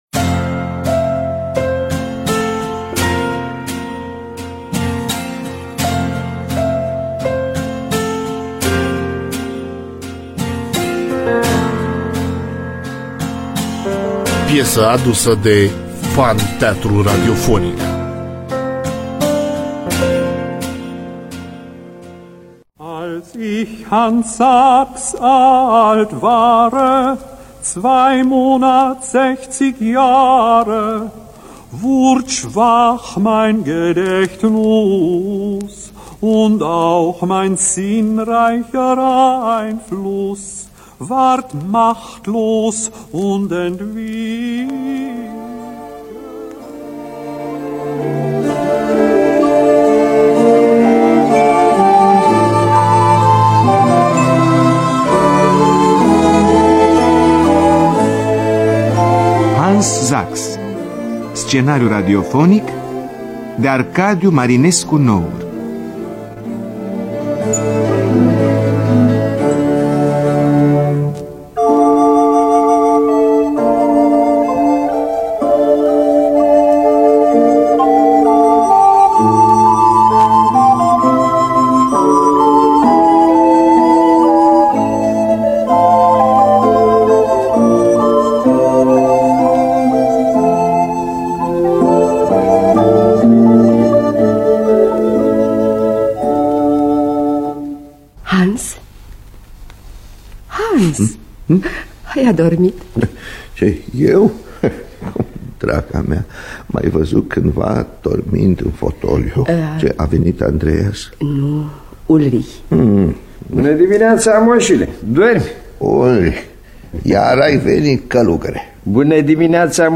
Biografii, Memorii: Hans Sachs (1976) – Teatru Radiofonic Online